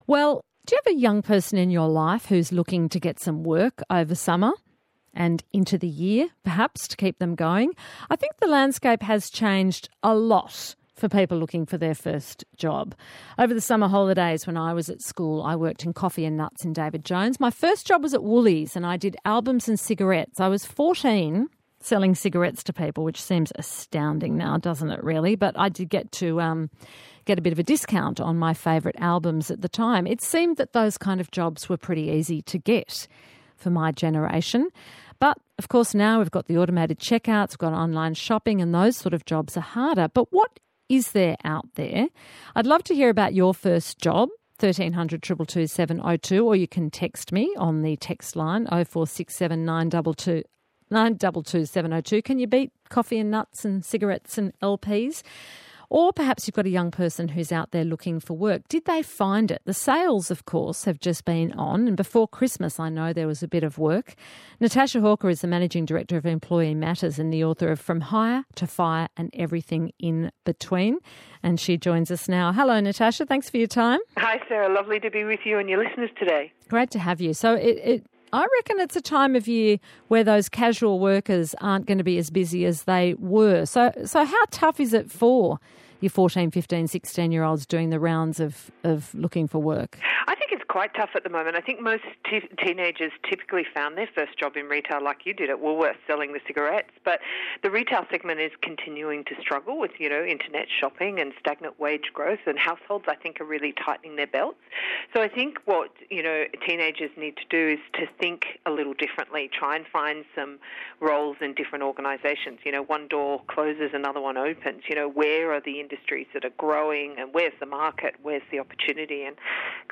on ABC Radio as they delve into key employee management topics and offer practical business advice.